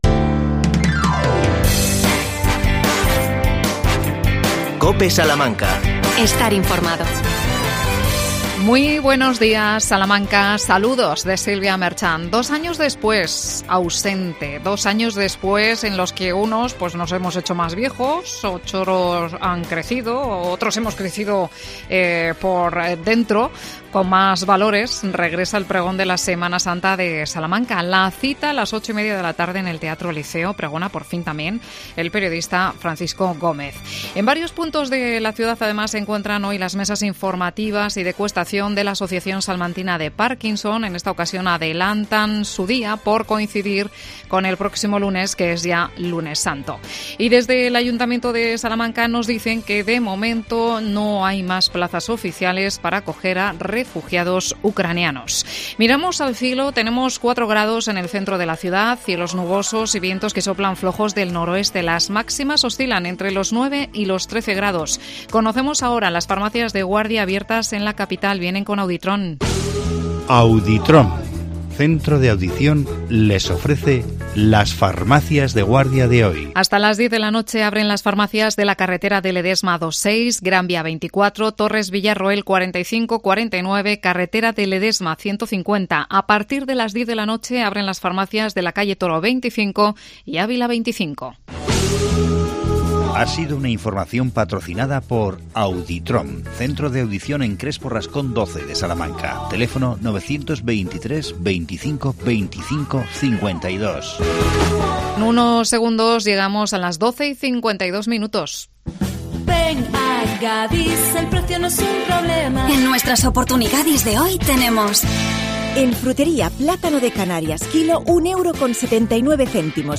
AUDIO: El programa Salamanca Acompaña, premio Fundos. Entrevistamos a la concejala de Mayores Isabel Macías.